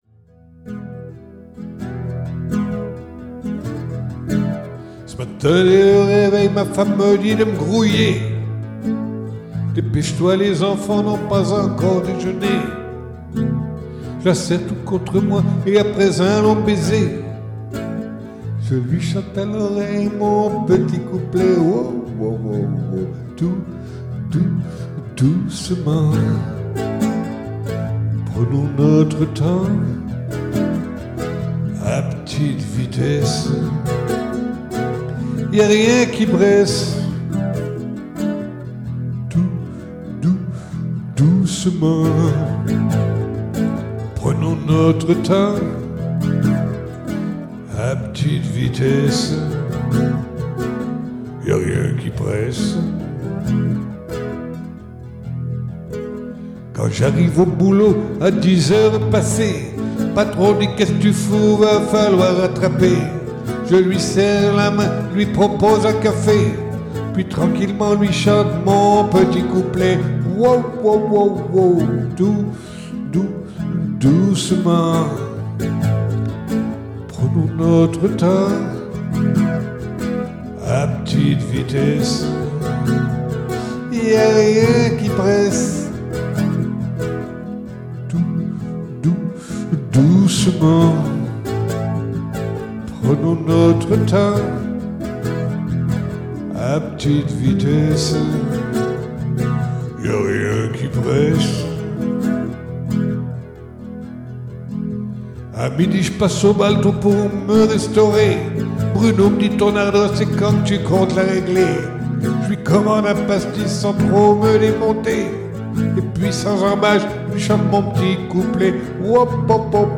Humour